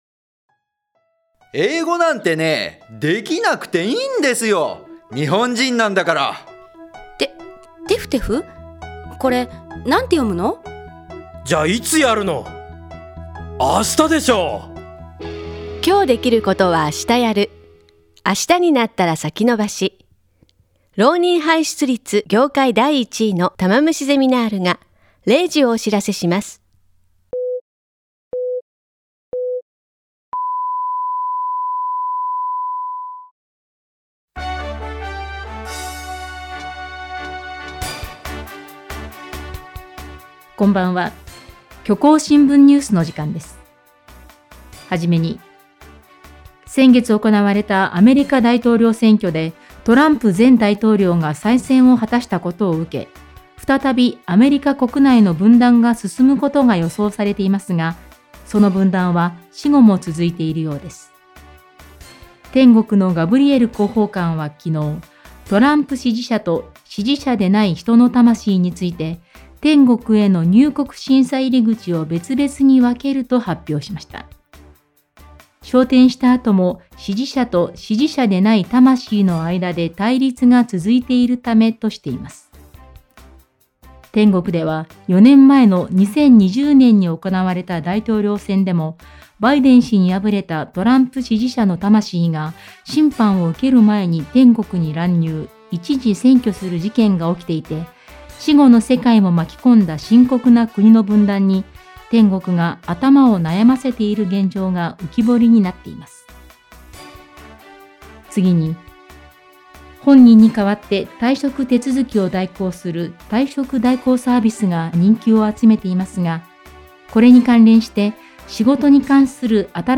「虚構新聞社」とインターネット放送局「プレイ」がお届けするニュース番組。世の中の様々なニュースをお届けしていきます。